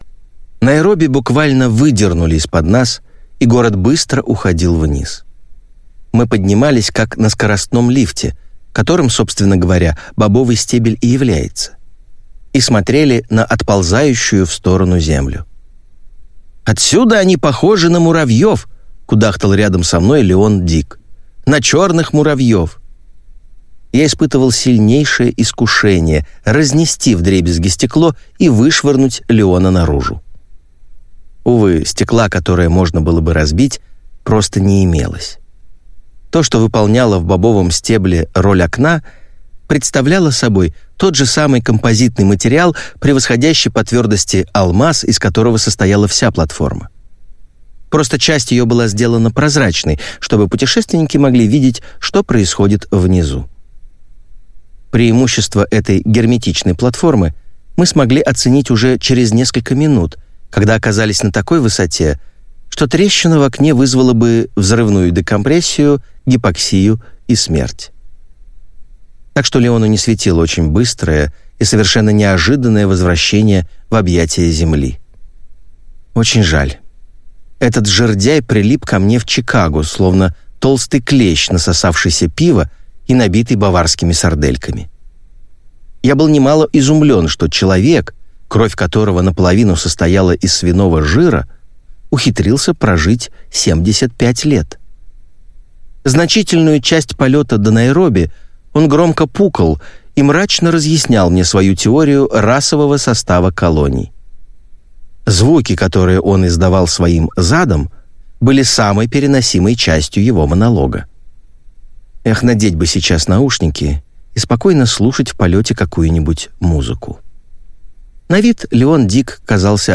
Аудиокнига Война старика | Библиотека аудиокниг